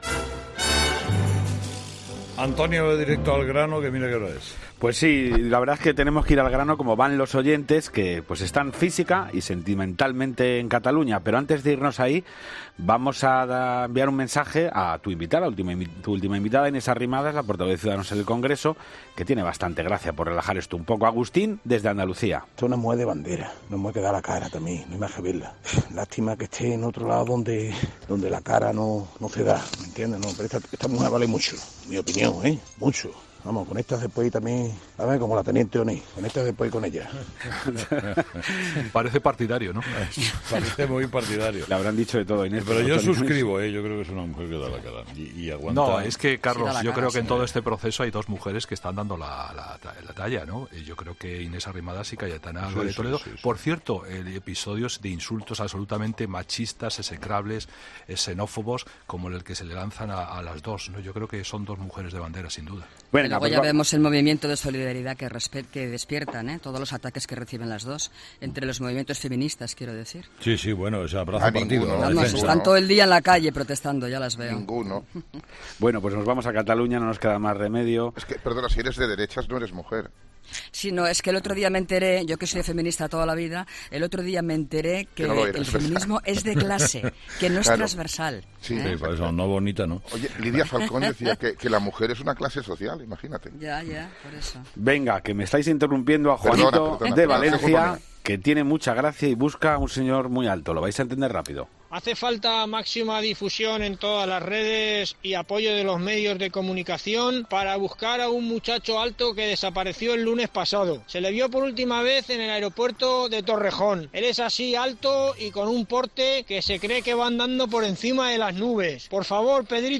Avalancha de mensajes en el contestador de ‘Herrera en COPE’. Nuestros oyentes continúan preocupados por la situación de Cataluña, pero antes han querido mandar un mensaje a la última invitada del programa; Inés Arrimadas, portavoz de Ciudadanos en el Congreso.